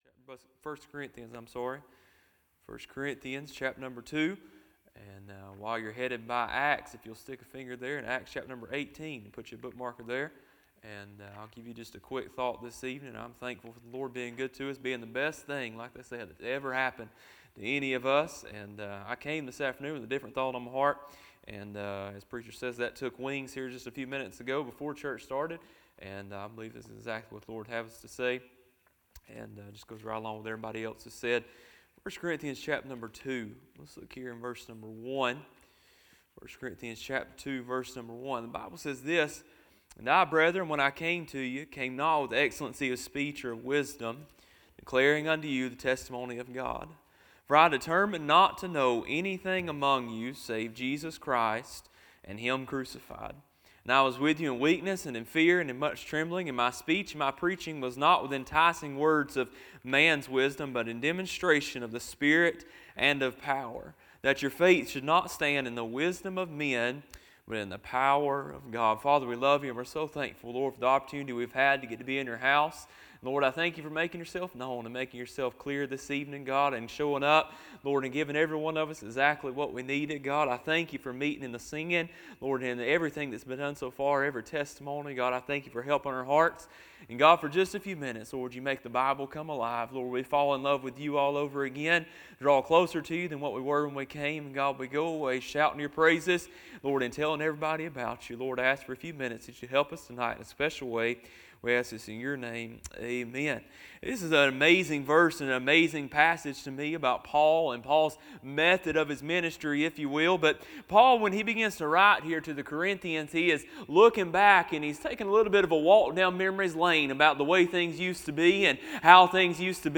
A sermon preached Wednesday Evening, on March 11, 2026.